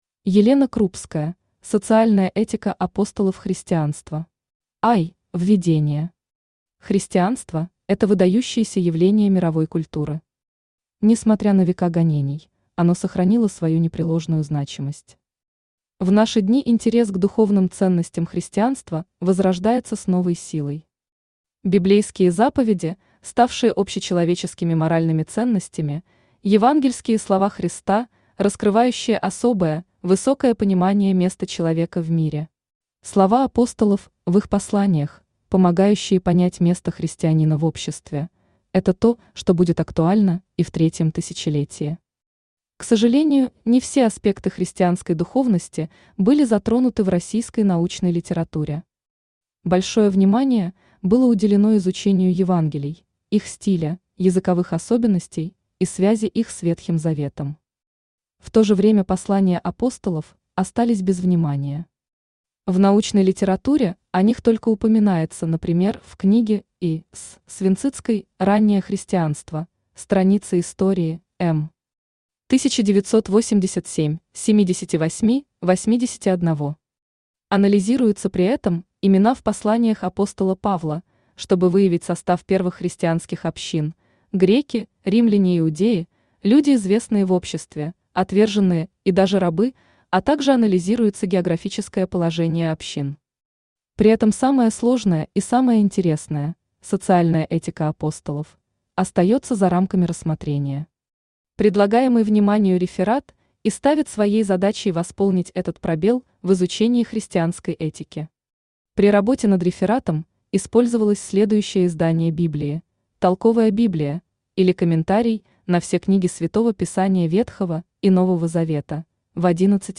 Аудиокнига Социальная этика апостолов христианства | Библиотека аудиокниг
Читает аудиокнигу Авточтец ЛитРес.